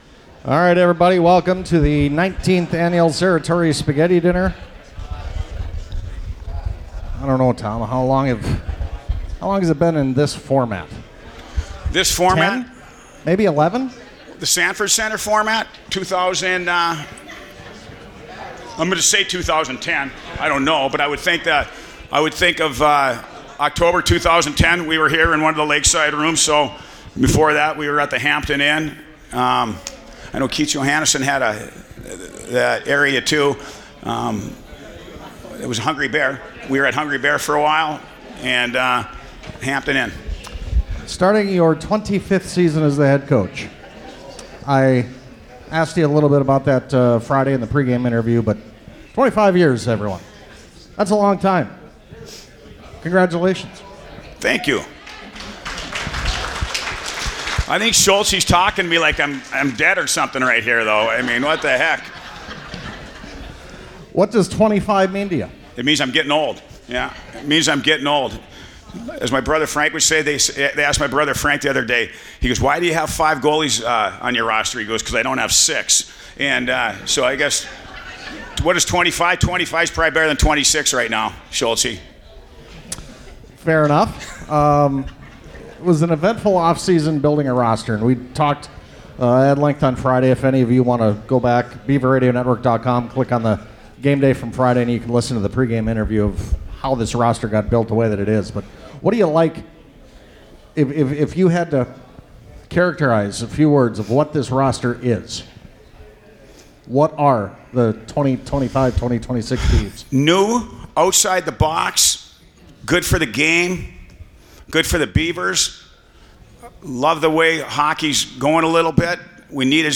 The 19th annual Serratore Spaghetti Dinner was tonight at the Sanford Center. Hear from all the men’s players and coaches at the archive here